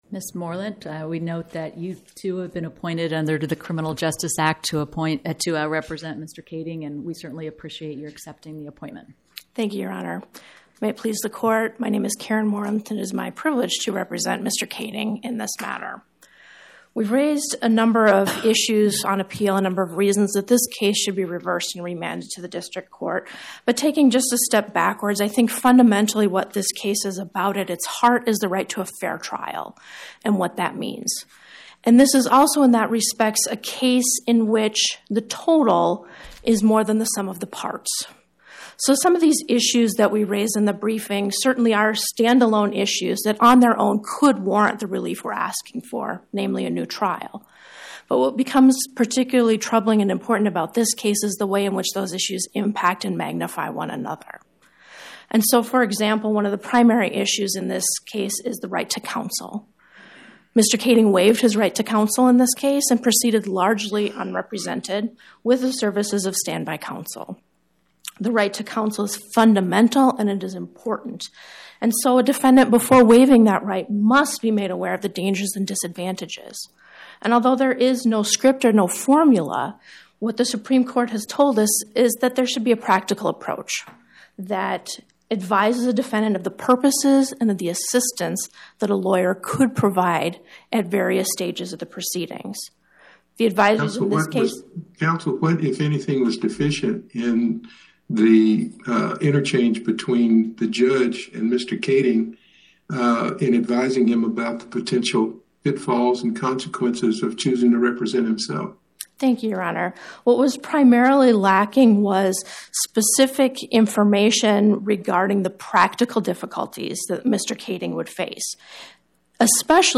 Oral argument argued before the Eighth Circuit U.S. Court of Appeals on or about 10/21/2025